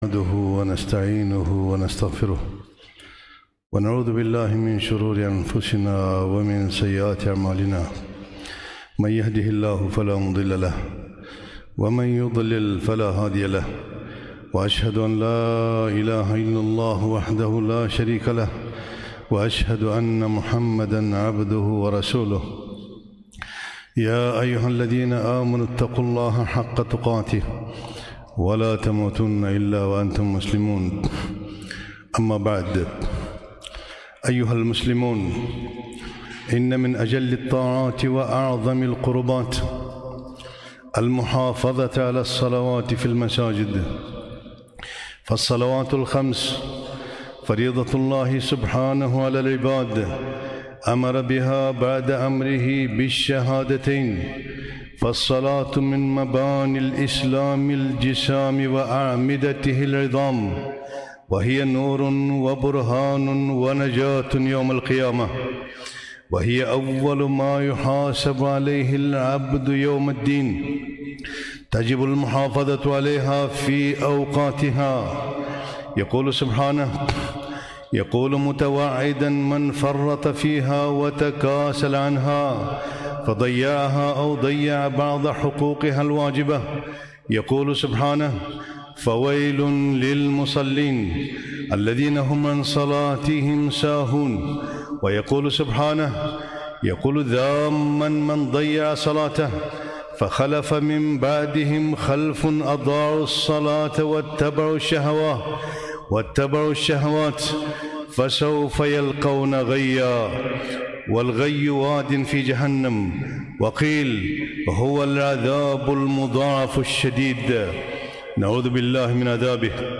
خطبة - فضل الصلاة في المسجد